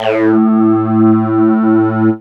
tekTTE63035acid-A.wav